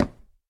Minecraft Version Minecraft Version snapshot Latest Release | Latest Snapshot snapshot / assets / minecraft / sounds / block / dried_ghast / wood4.ogg Compare With Compare With Latest Release | Latest Snapshot
wood4.ogg